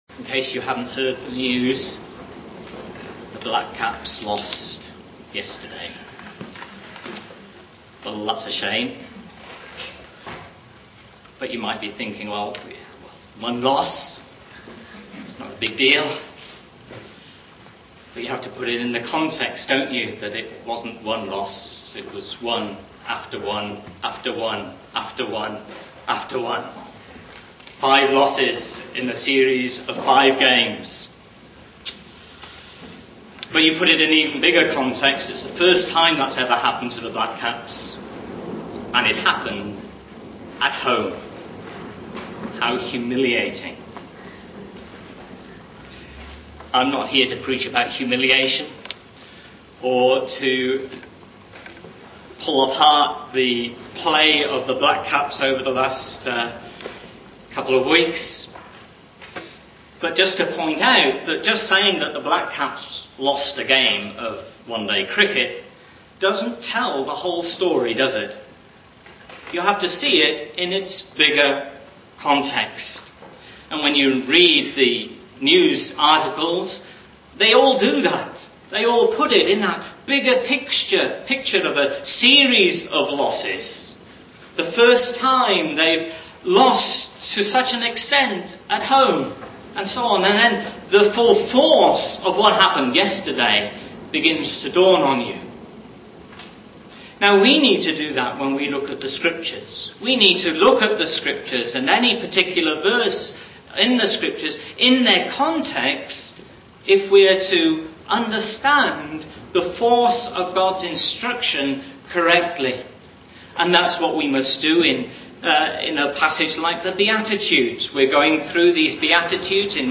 GBC Sermon Archive